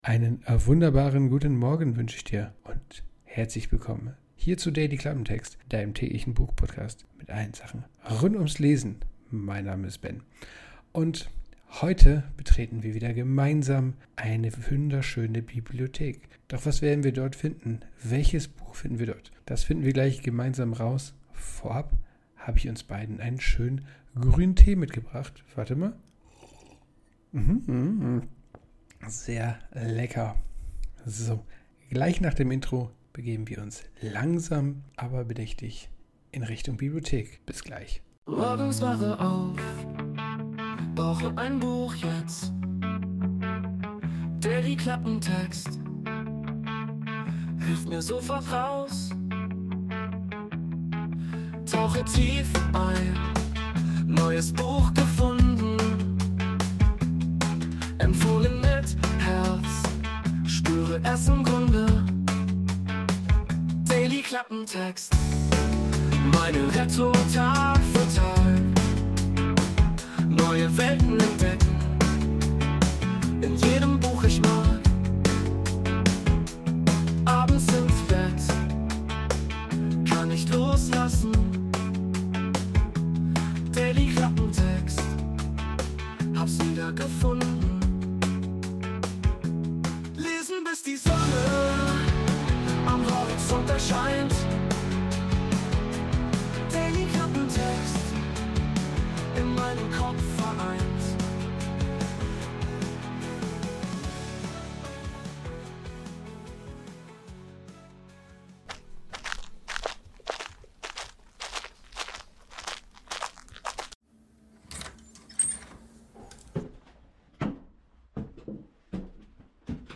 Emotional Cello Main
Intromusik: Wurde mit der KI Suno erstellt.